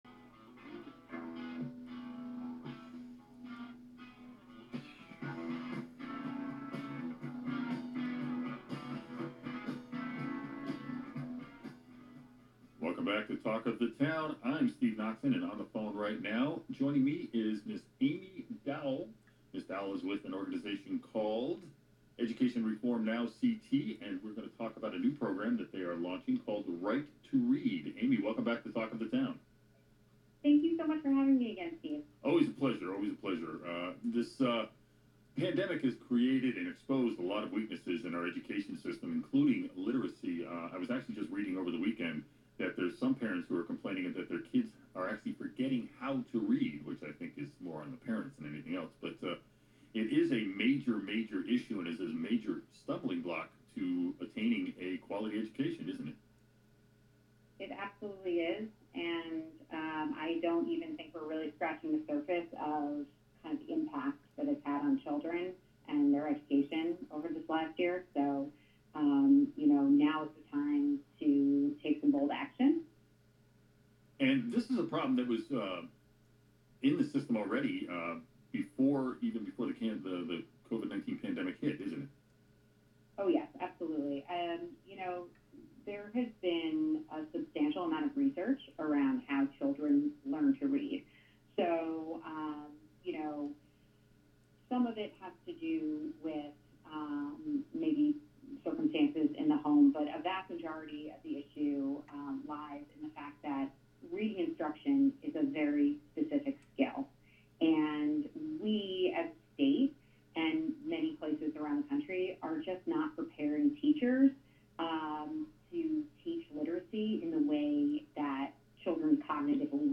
[INTERVIEW] WATR'S "Talk of of the Town"